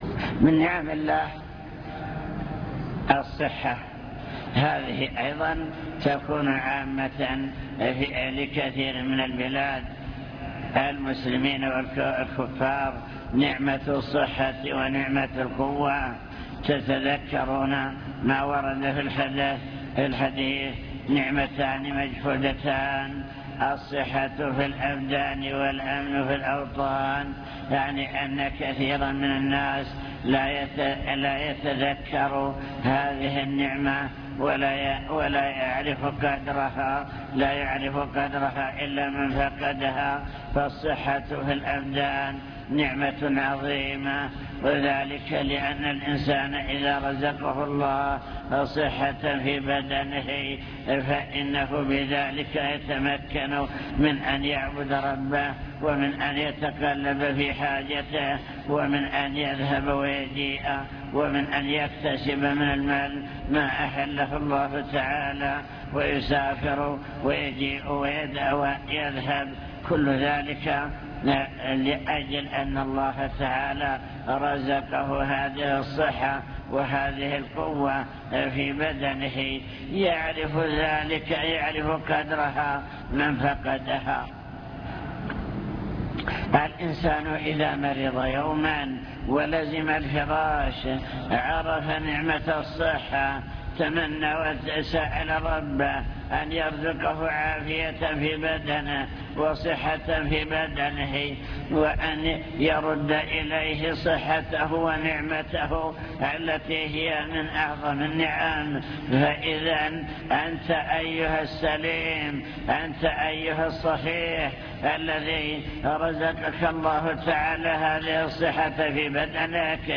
المكتبة الصوتية  تسجيلات - محاضرات ودروس  محاضرة بعنوان شكر النعم (1) ذكر نماذج لنعم الله تعالى العامة وكيفية شكرها